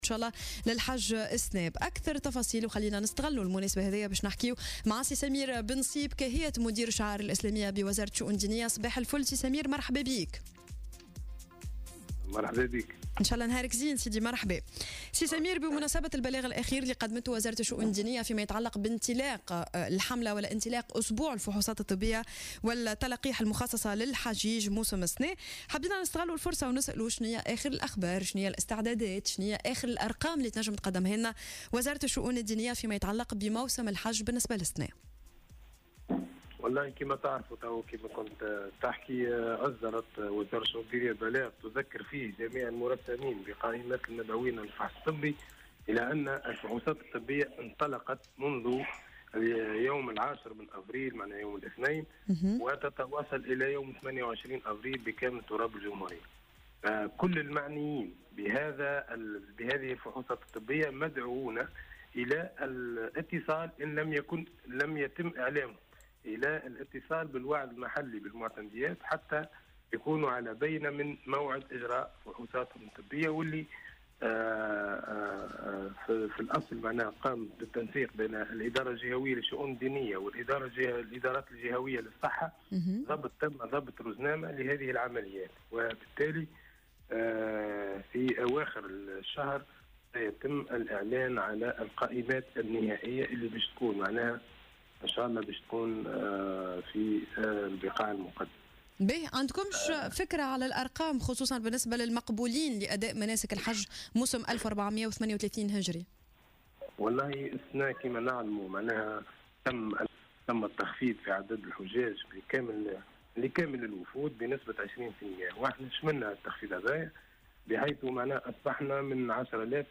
في مداخلة له على الجوهرة "اف ام"